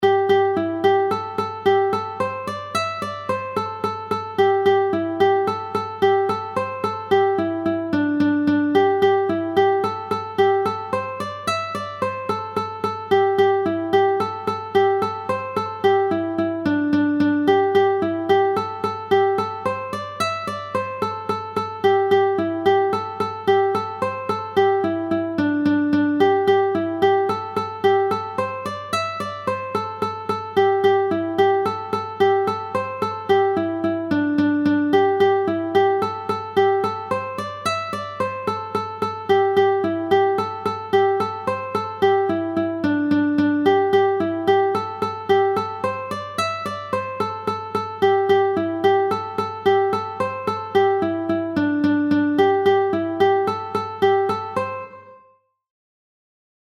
Mélodies et accords